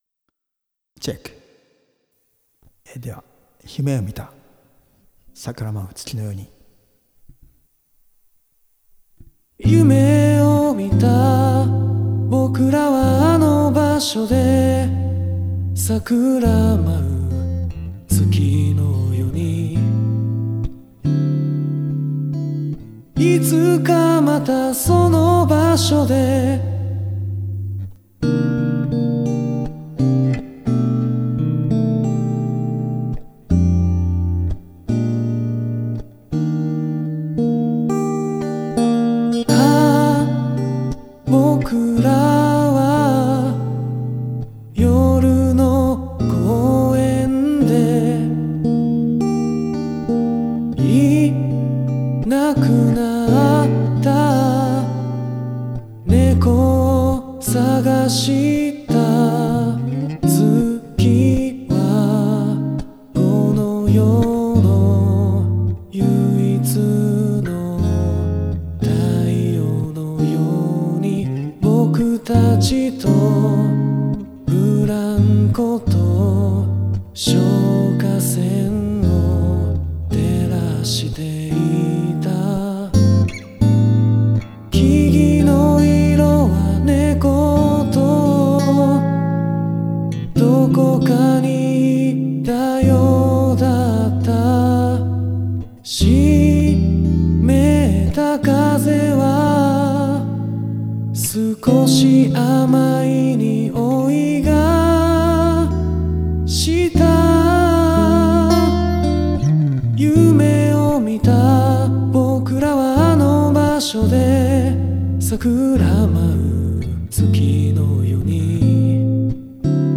ネコツーラジオでやったスタジオライブから。